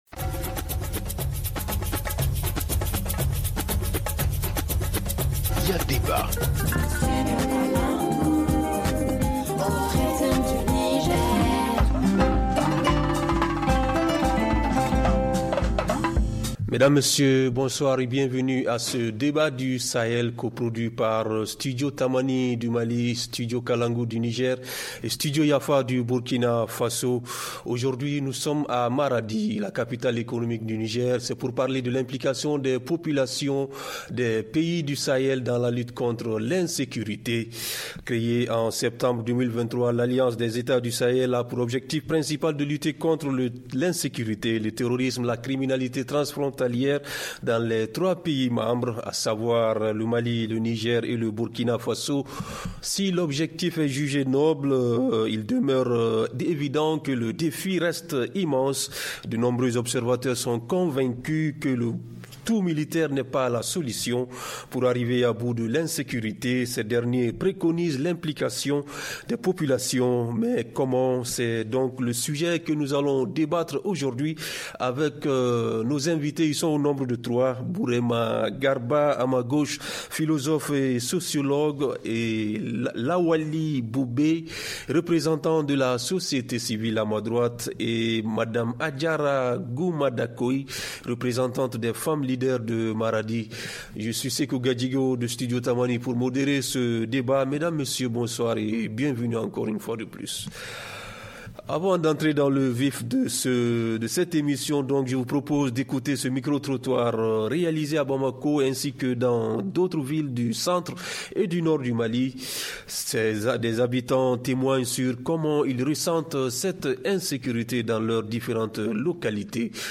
Mesdames messieurs bonsoir, bienvenu à ce débat coproduit par Studio Tamani du Mali, Studio Kalangou du Niger et Studio Yafa du Burkina Faso, trois médias de la fondation hirondelle. Aujourd’hui nous sommes à Maradi, en République du Niger. C’est pour parler de l’implication des populations des pays de l’AES dans la lutte contre l’insécurité.